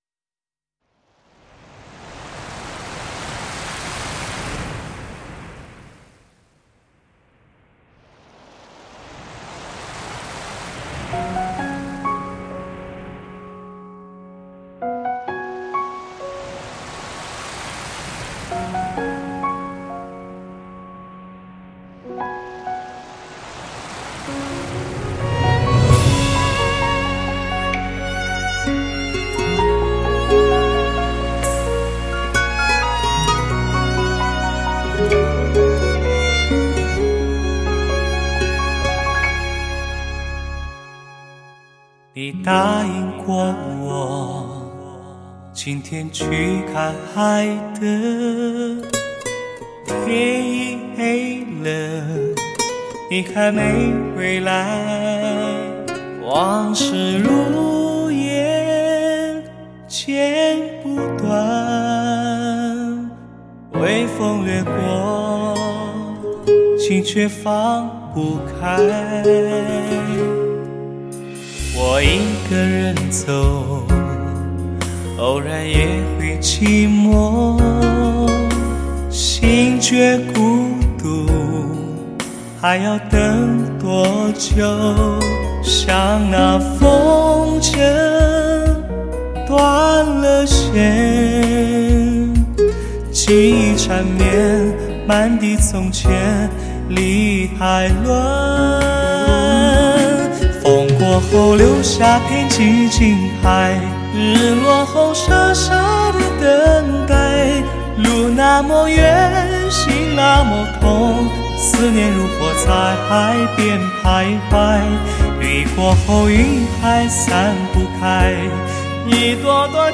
独一无二的磁性嗓音 赋予歌曲别样韵味
大师级幕后制作团队，极致发烧的音响效果成为极品的珍藏。